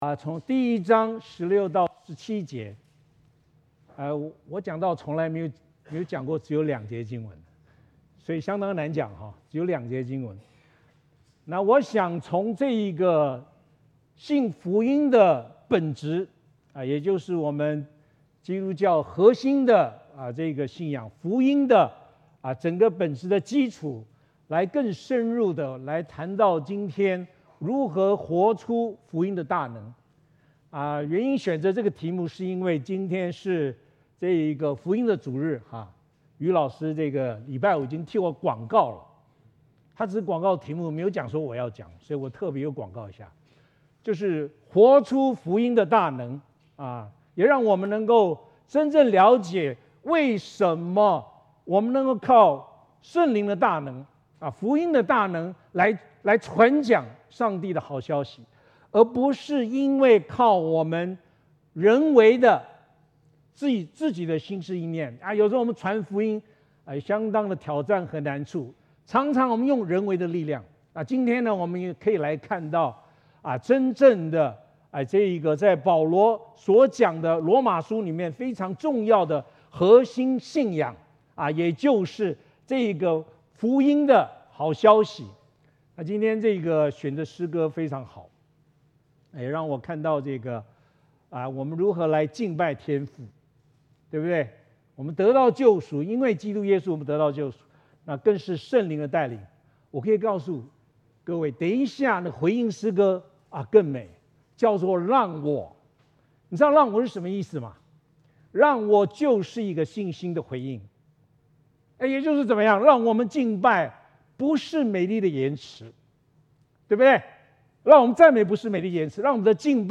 证道录音